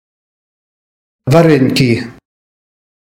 prononciation_vareneki.mp3